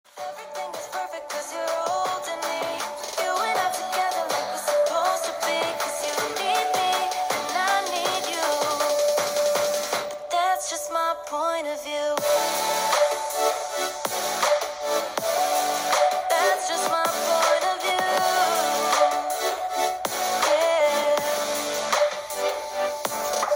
Zenfone 8は、ステレオスピーカーを搭載。肝心の音については、クリアで臨場感ある音を楽しめます。
▼Zenfone 8のステレオスピーカーの音はこちら！